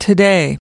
1.Today  /təˈdeɪ/ : hôm nay